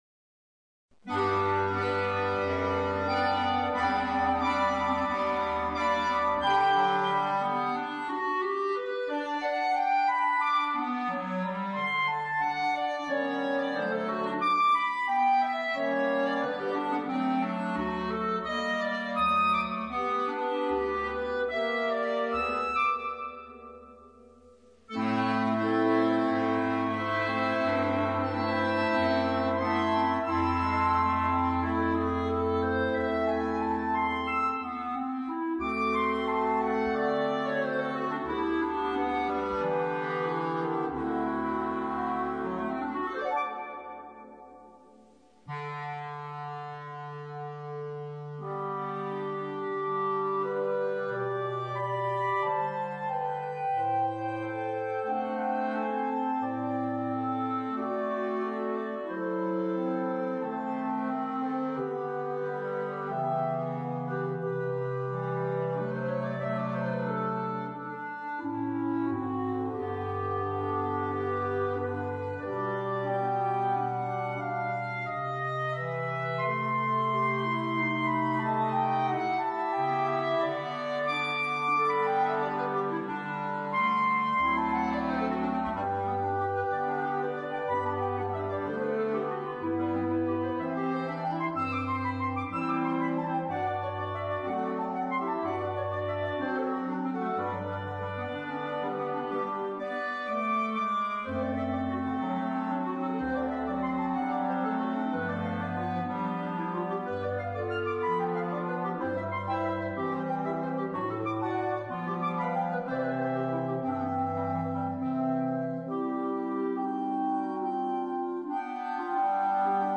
per clarinetto solista e coro di clarinetti